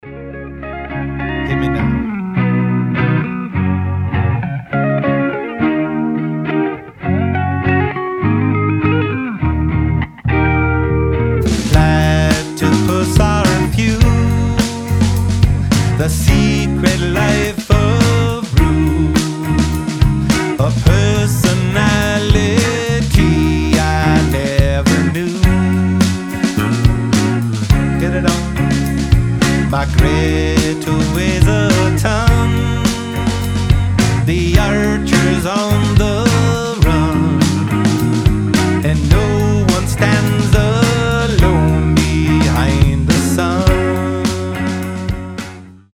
• Качество: 320, Stereo
гитара
мужской голос
Alternative Rock
Funk Rock